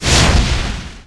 bullet_flame_ball.wav